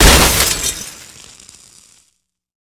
smash.ogg